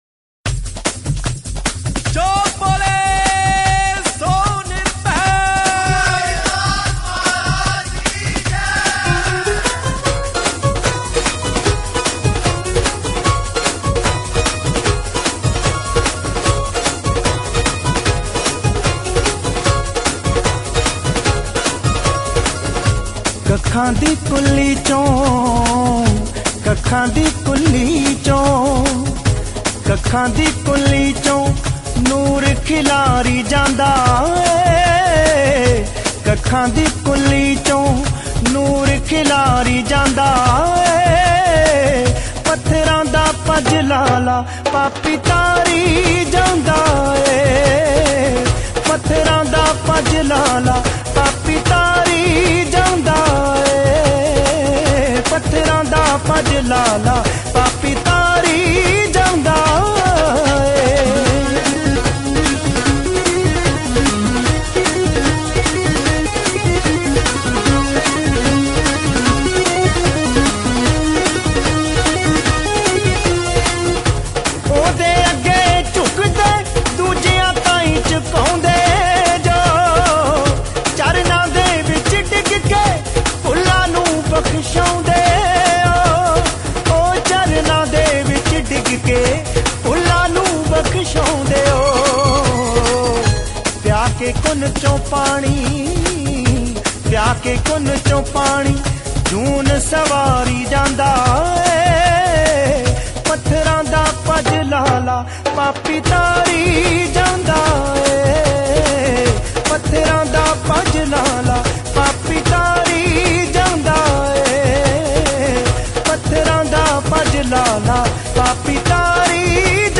Category: Bhakti Sangeet